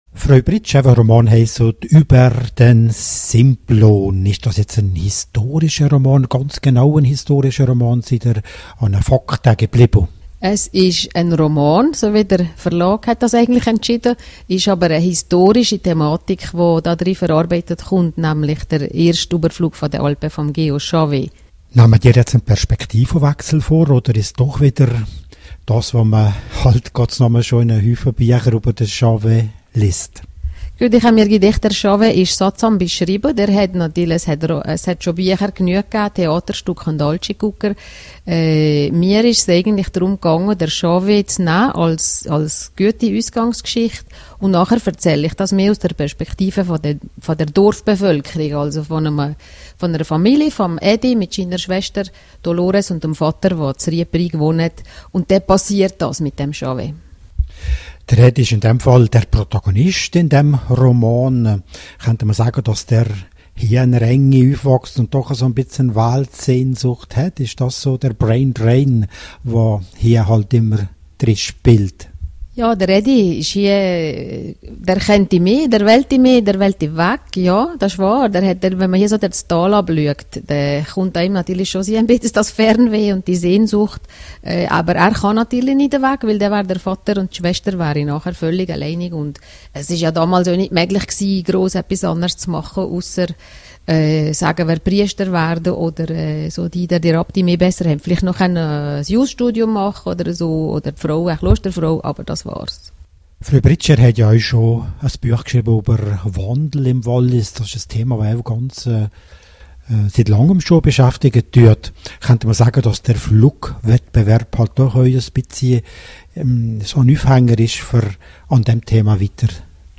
Interview mit rro